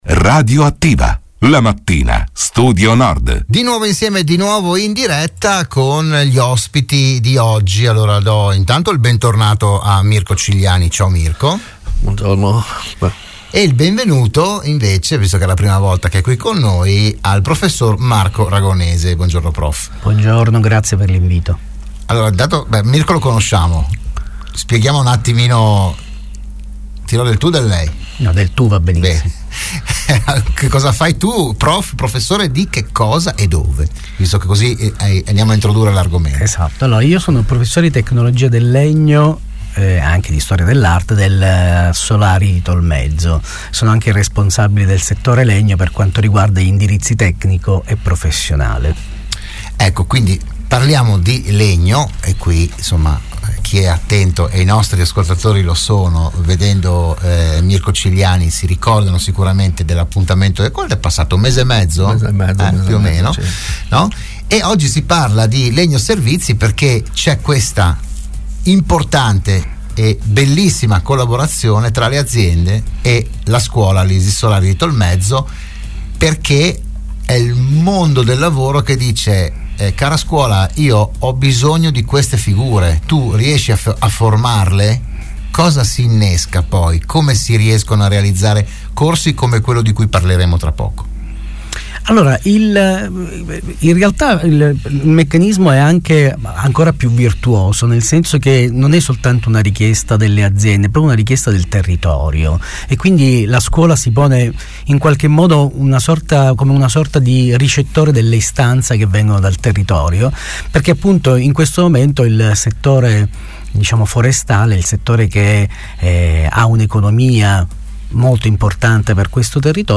L'indirizzo nasce dalla collaborazione tra scuola, imprese e sistema produttivo della filiera bosco-legno. Ne hanno parlato a Radio Studio Nord